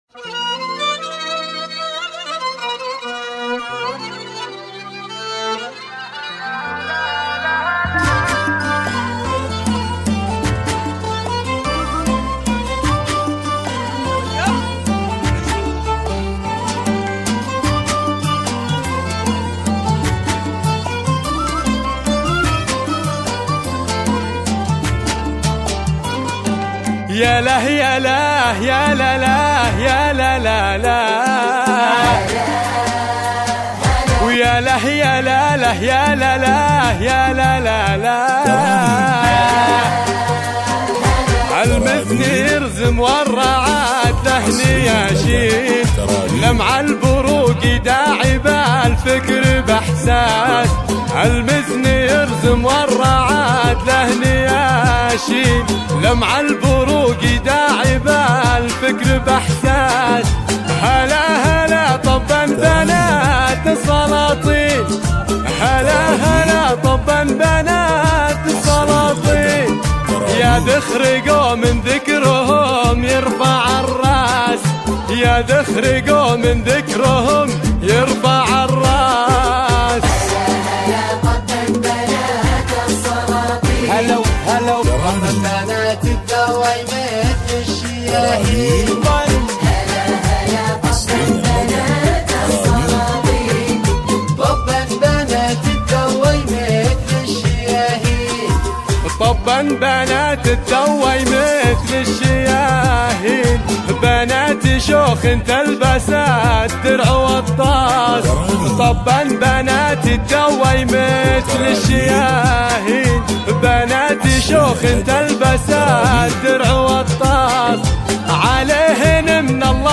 زفات